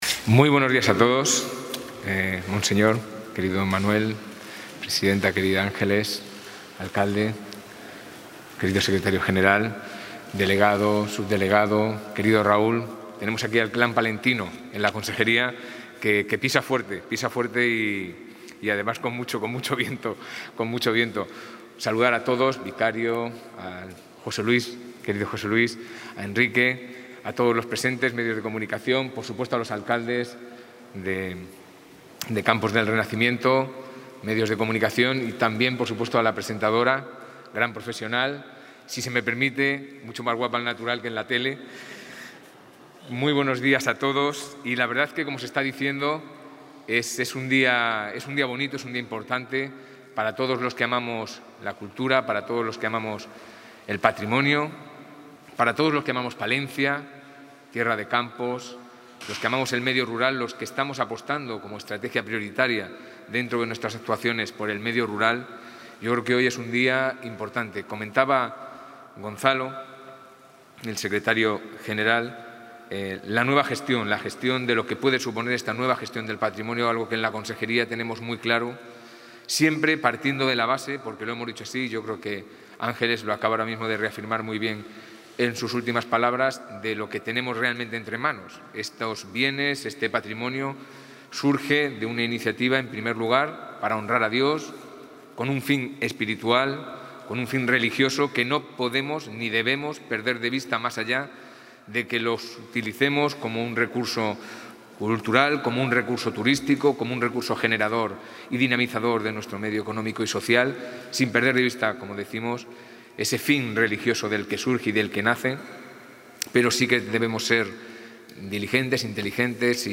Intervención del consejero de Cultura y Turismo.
Paredes de Nava ha acogido esta mañana el acto de inauguración de este proyecto, fruto de la colaboración y coordinación entre instituciones, tras tres años de intenso trabajo.